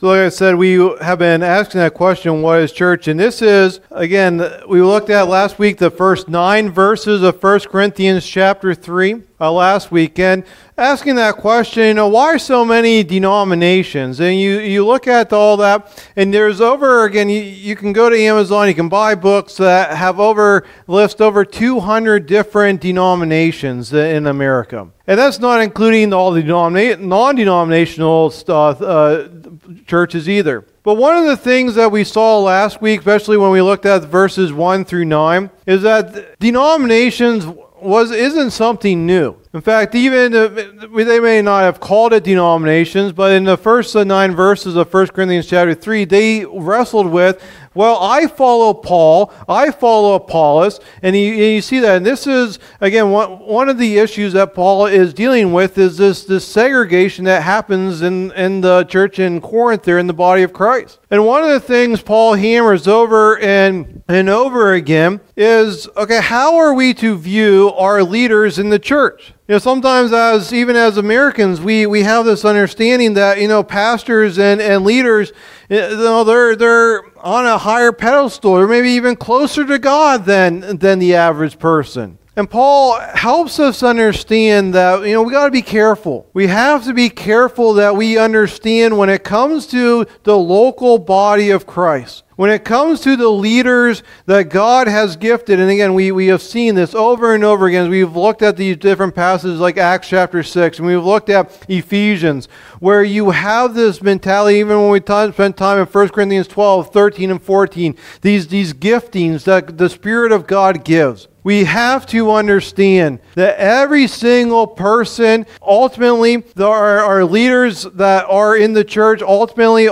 Message #17 in the "What is Church?" teaching series